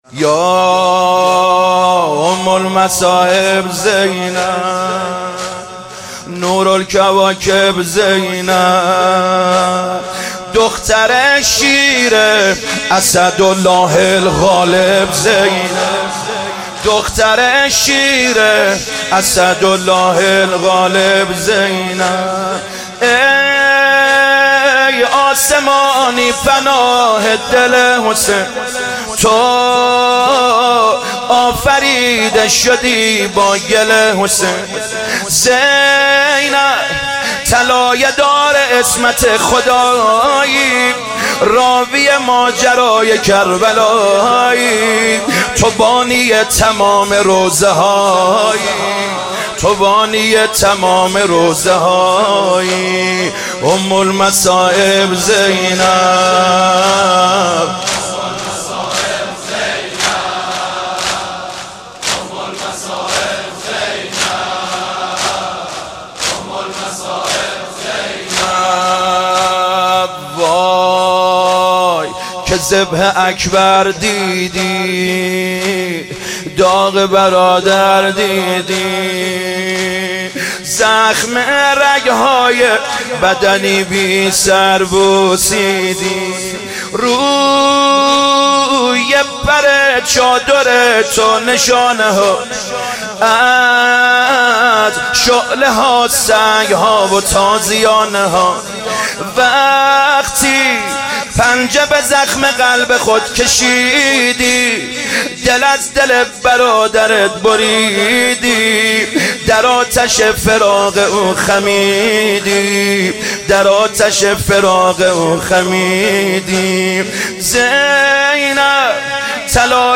مداحی جدید
شب چهارم محرم۹۸ هیات کربلا رفسنجان